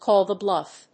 アクセントcáll the [a person's] blúff